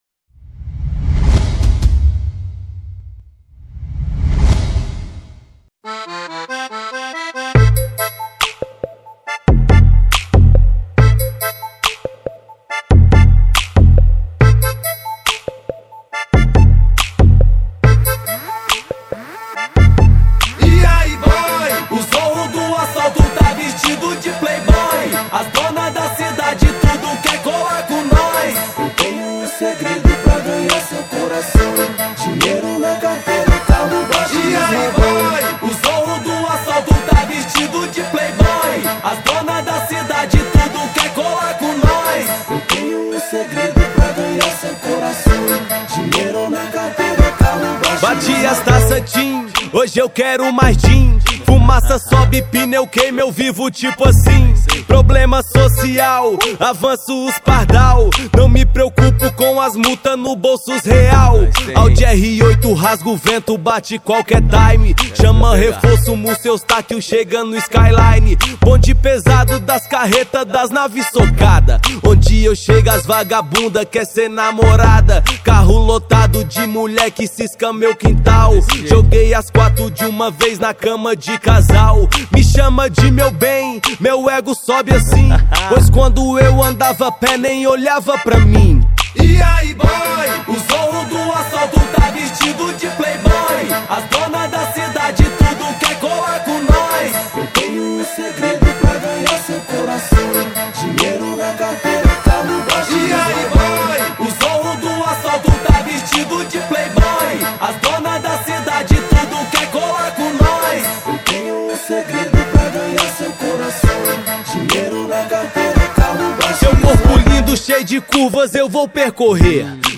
2025-01-02 16:37:44 Gênero: Hip Hop Views